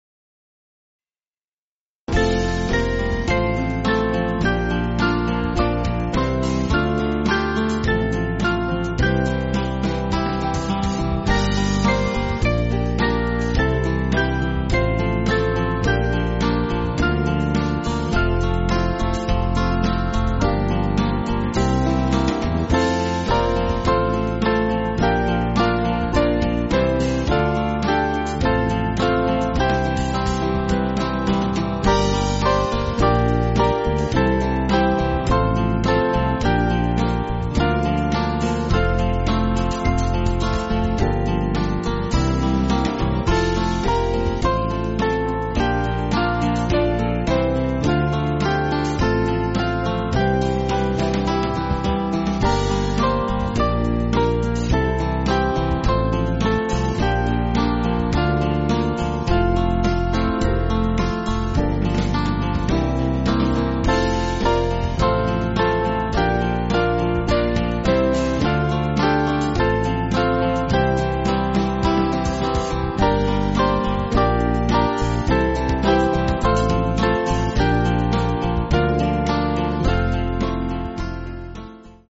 Liturgical Music
Small Band